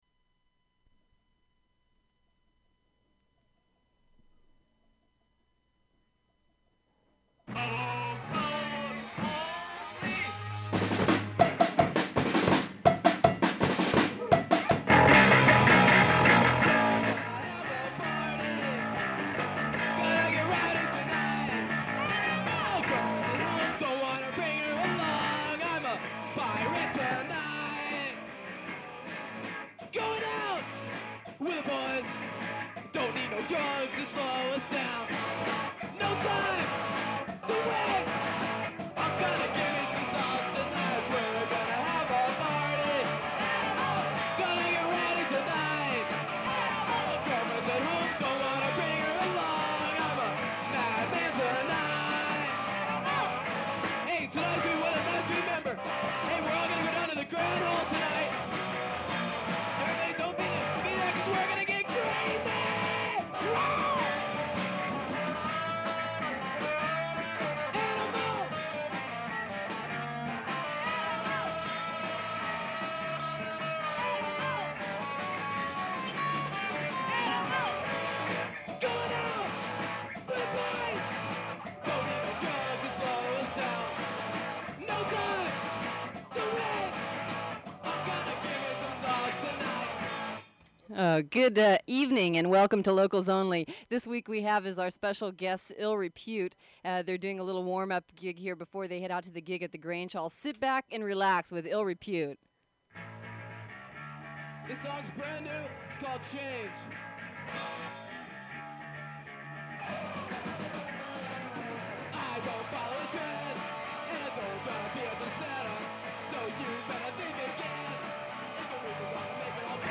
a hardcore punk band